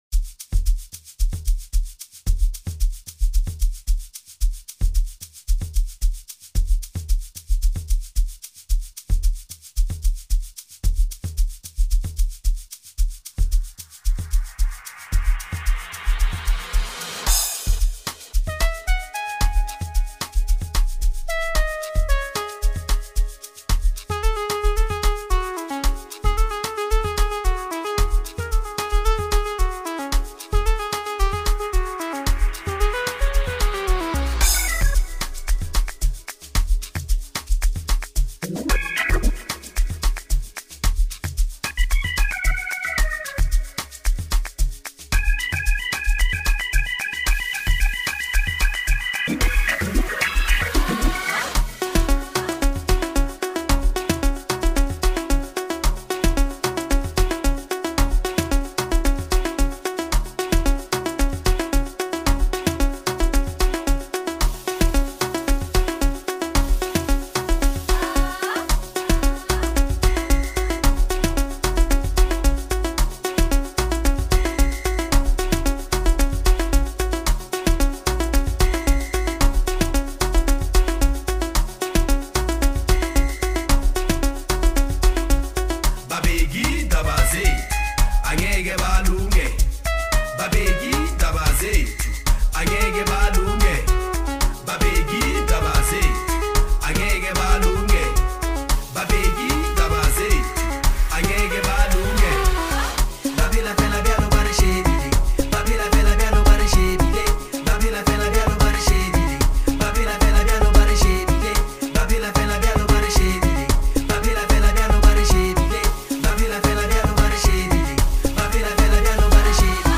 banging tune